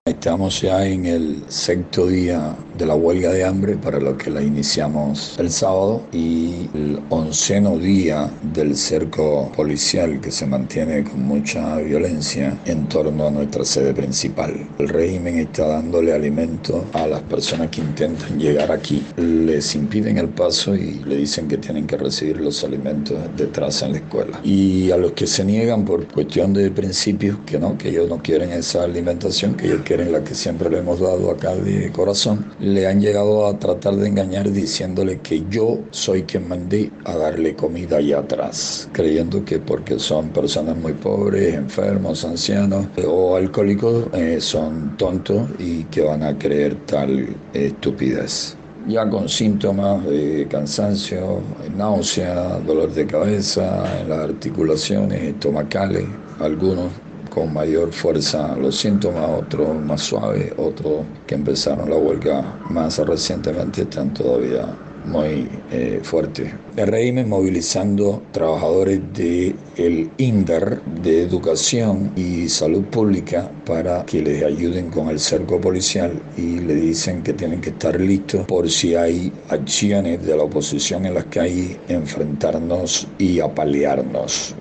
El opositor José Daniel Ferrer, líder de UNPACU, conversó con Radio Martí sobre el estado de los huelguistas y las maniobras que el régimen implementa para tratar de socavar la labor humanitaria de la organización.
Declaraciones de José Daniel Ferrer a Radio Martí